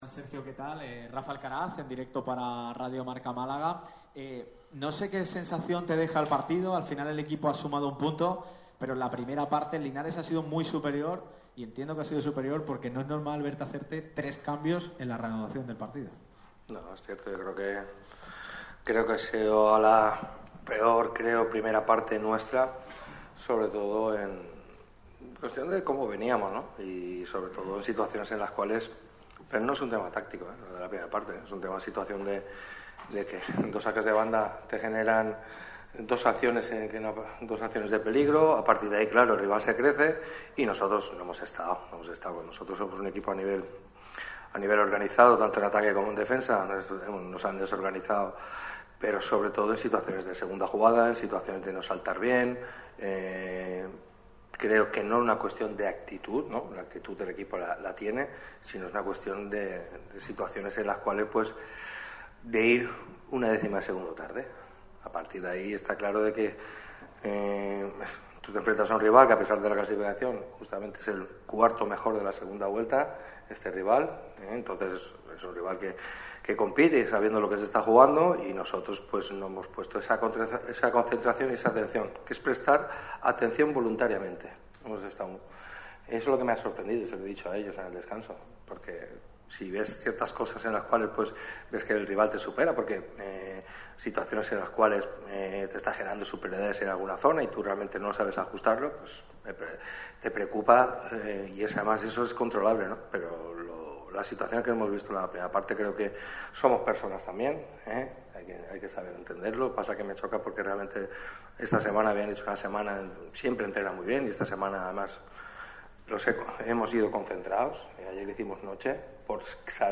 Sergio Pellicer ha comparecido en la rueda de prensa posterior al partido del Málaga CF frente al Linares. El técnico de Nules ha analizado el empate de este domingo en casa. También ha hablado de lo que le viene al Málaga y del regreso de Ramón, entre otras muchas cosas.